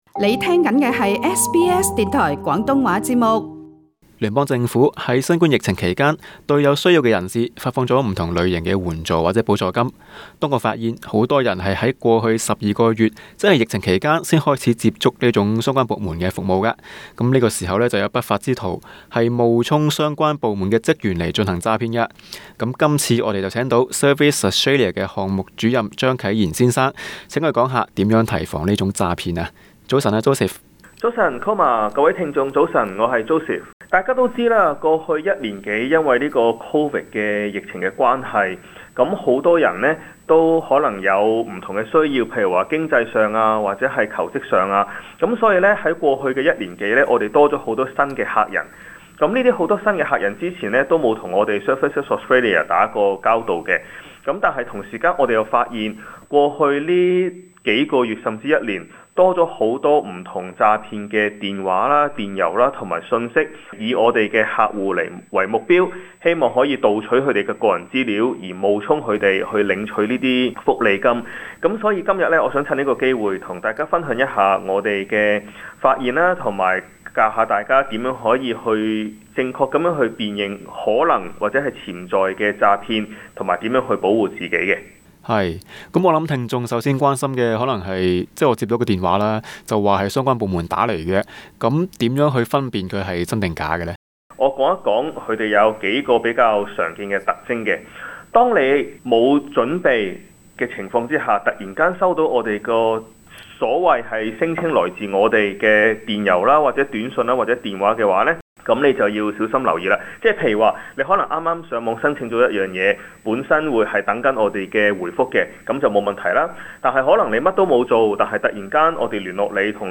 【社区访问】